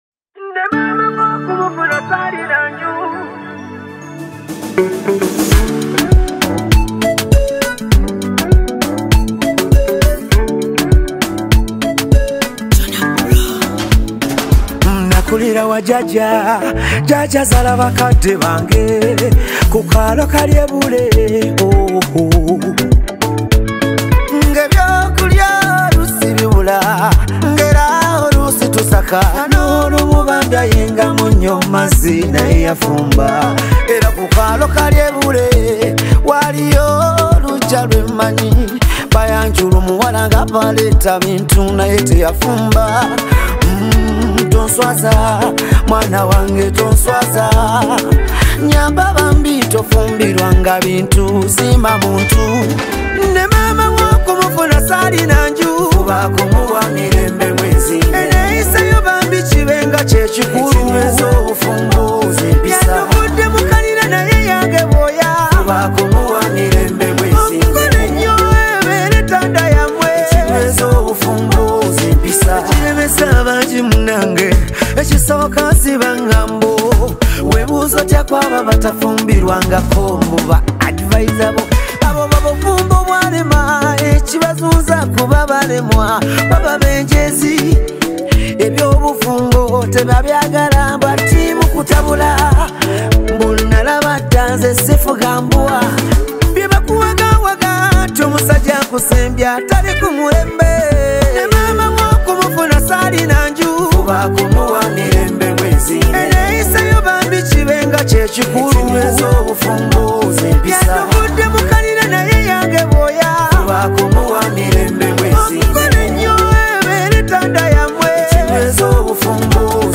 powerful Luganda song
In this heartfelt track